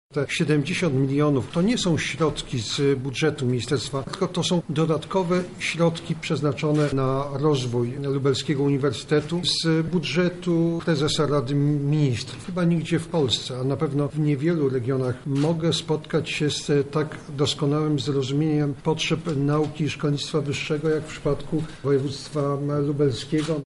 – To jest bardzo dobry dzień dla polskiej nauki – mówi wicepremier, minister nauki i szkolnictwa wyższego Jarosław Gowin: